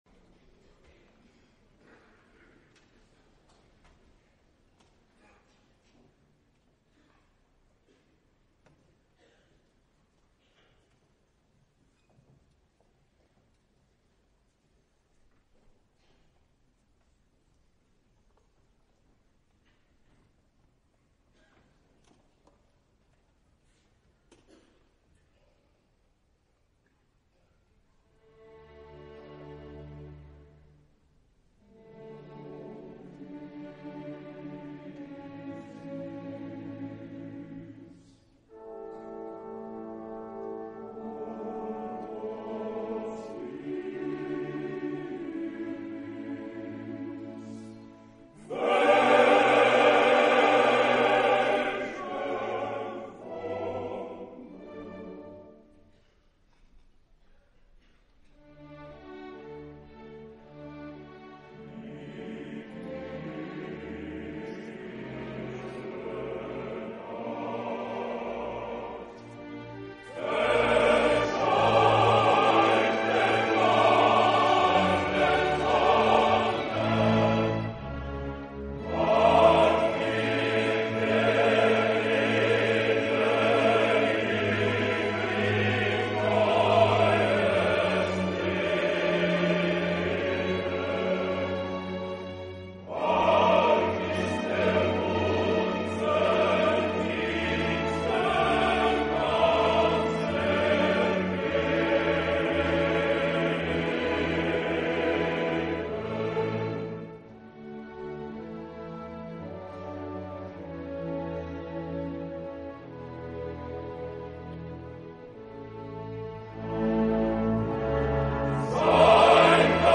Version originale
par Chorale Mélisande
H10049-Live.mp3